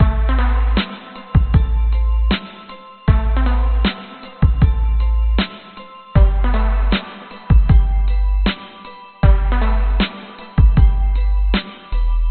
描述：Trippy cats income message sound made from my 4 cat's meows.
标签： income trippy cats message phone
声道立体声